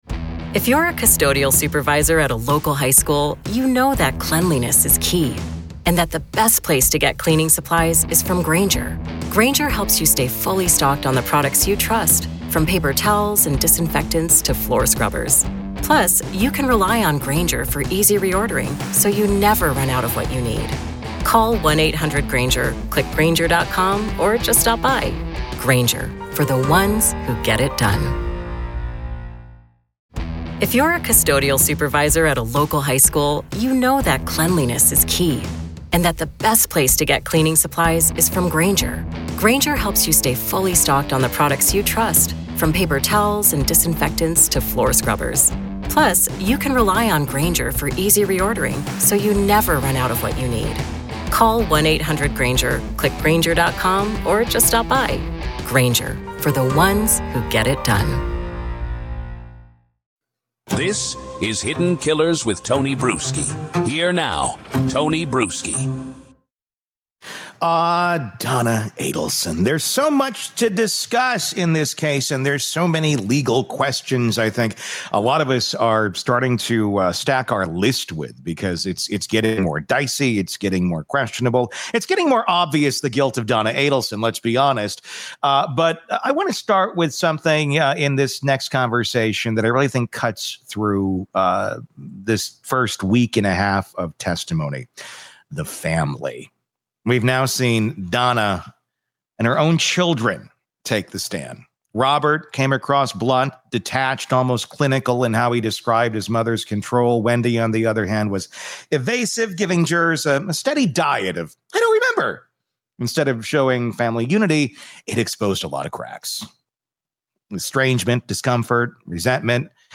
defense attorney and former prosecutor